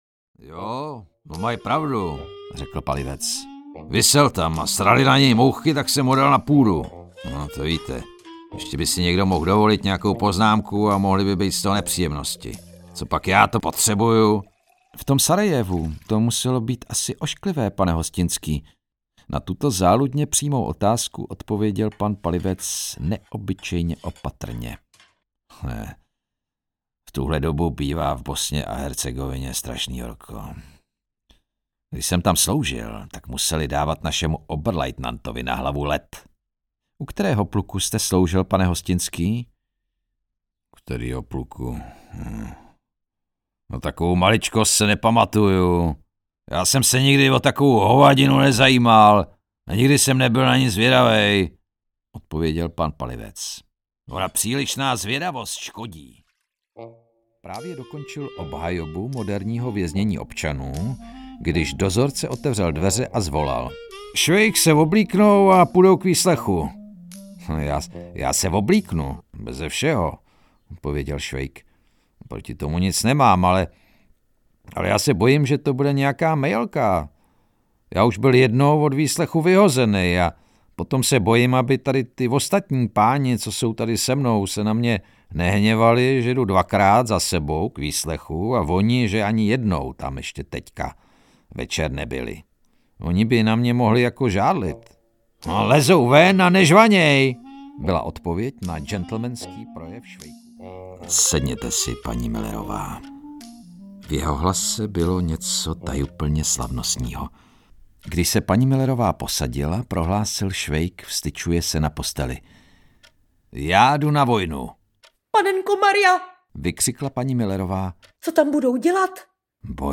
Švejkovy bestofky 1 audiokniha
Ukázka z knihy